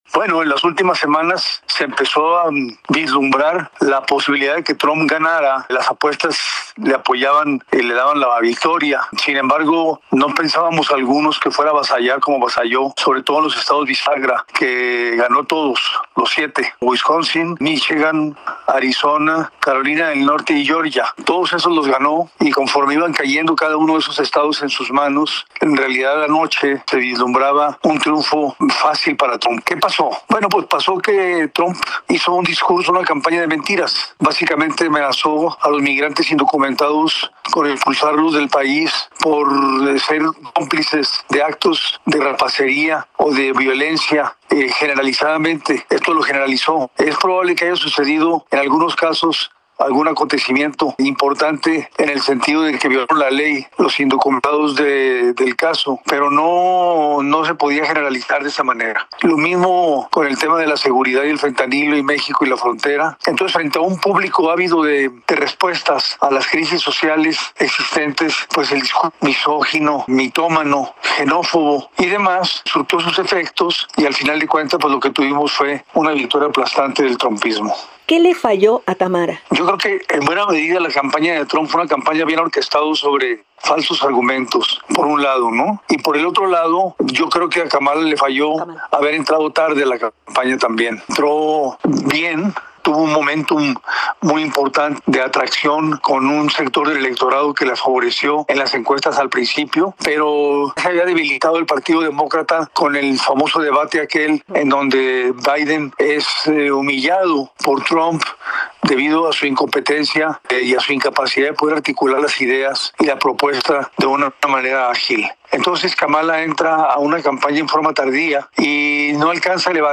10-ENTREVISTA-08-NOV.mp3